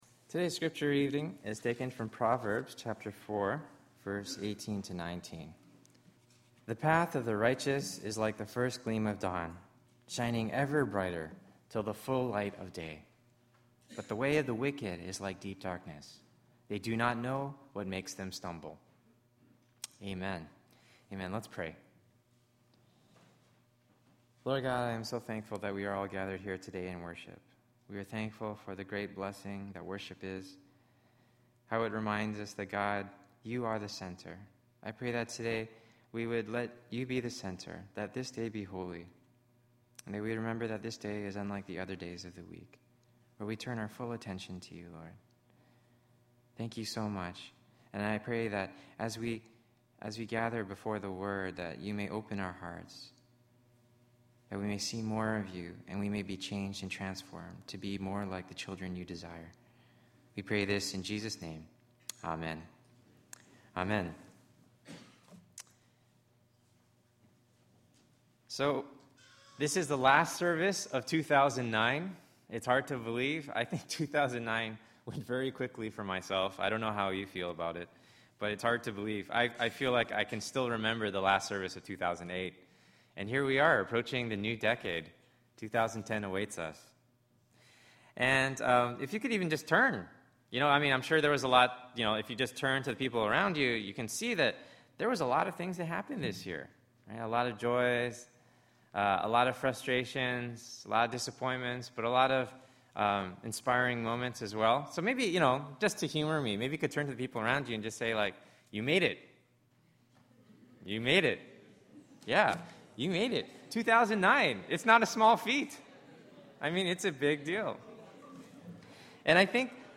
Light at the Darkest Time of the Year: December 27, 2009 Sermon